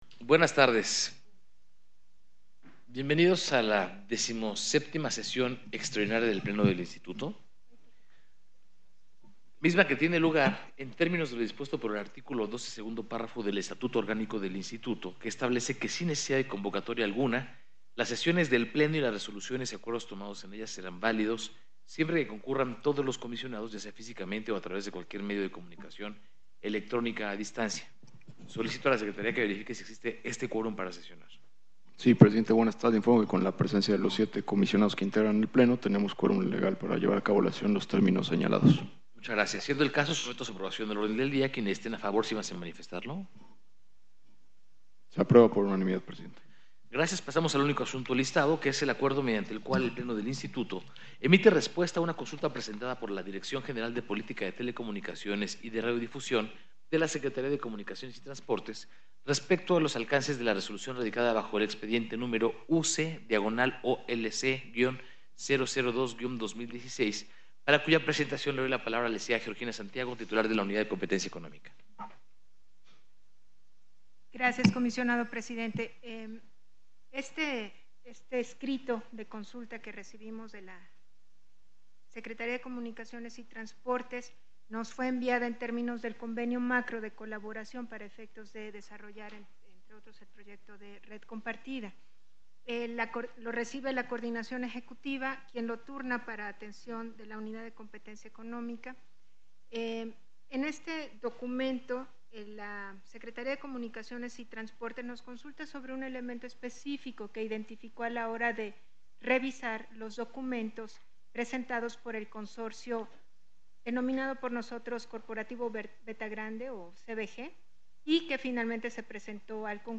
Audio de la sesión 1 (Versión Pública)